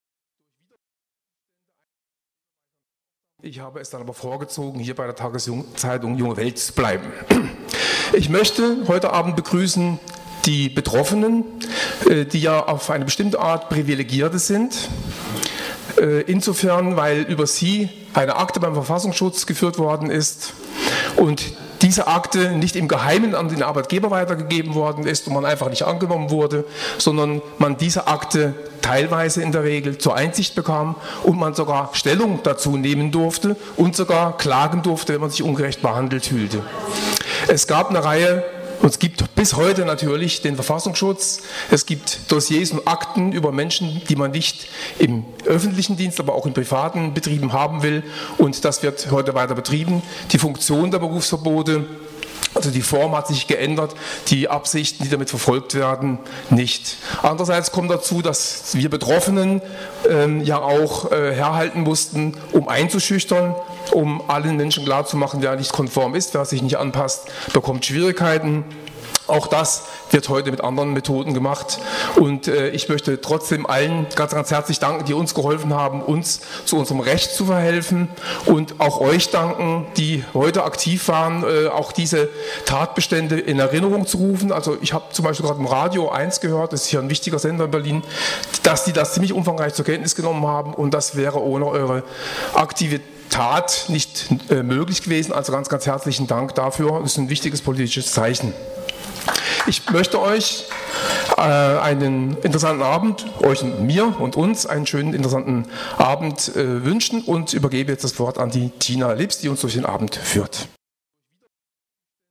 Polithistorische Revue mit 17 Zeitzeuginnen und Zeitzeugen aus 8 Bundesländern,
Begrüßung durch die Junge Welt